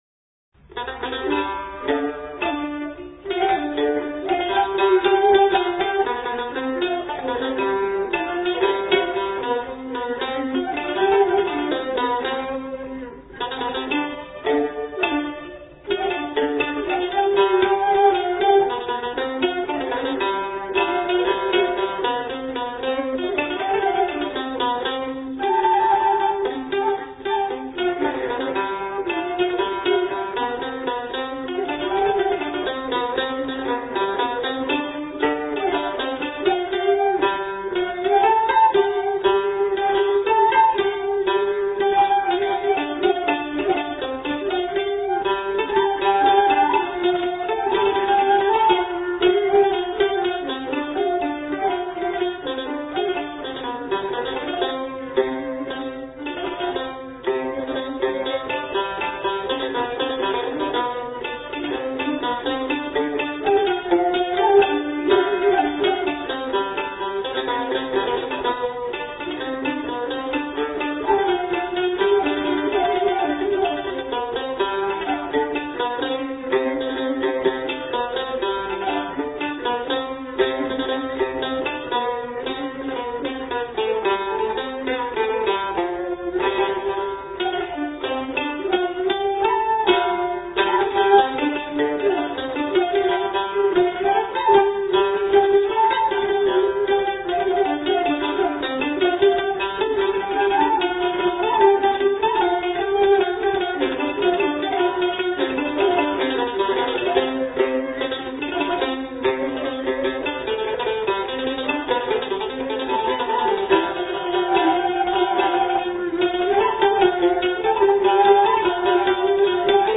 改編後旋律加花，並採用五度轉調演奏，使曲調變得明朗而歡快，完全是另一種意境。
曲調結構緊湊，激昂有力。